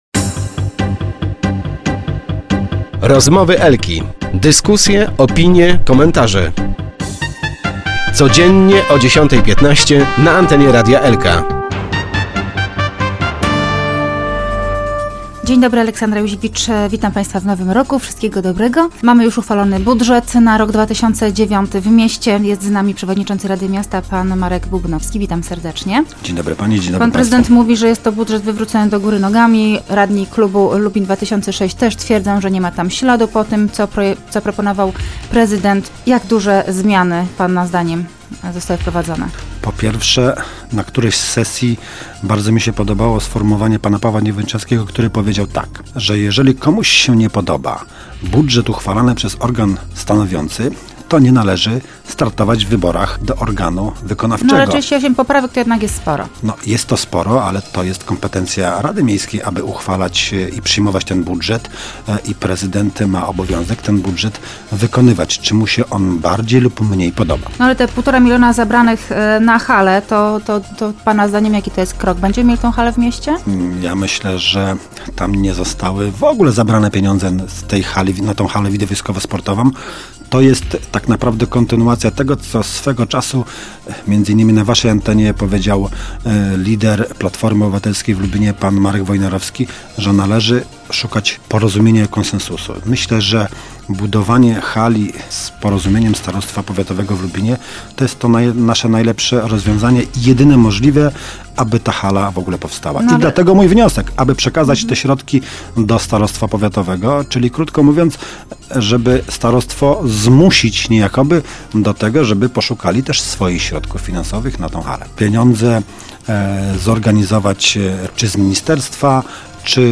Radni wnieśli 38 poprawek, nie udzielili poparcia na prawie 50 mln kredytu, a w związku z tym wydatki zaplanowane w budżecie są wyższe niż zaplanowane wpływy. Przewodniczący Rady Miasta Lubina Marek Bubnowski, gość Porannych Rozmów Elki, twierdzi, że wszystkie zmiany w budżecie były konieczne, a decyzja o zaciągnięciu kredytu została jedynie odroczona w obawie przed mniejszymi dochodami z powodu kryzysu gospodarczego w Europie.